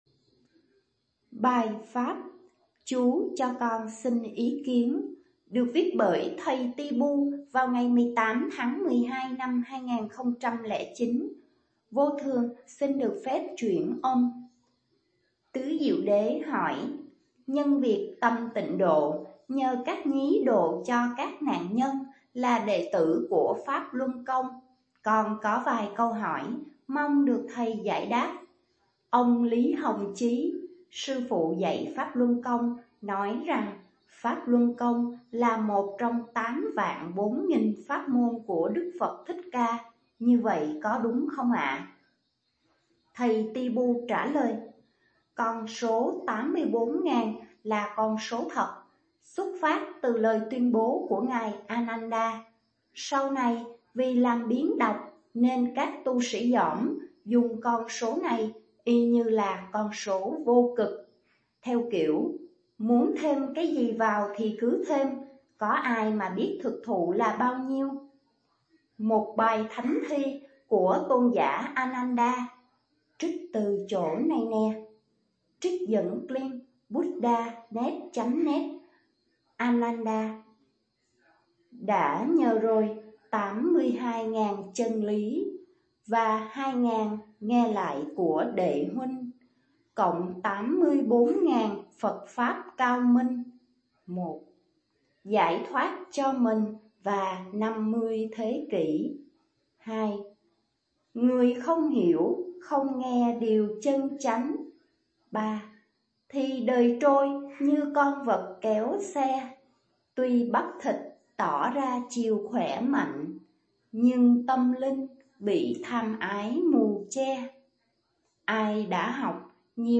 chuyển âm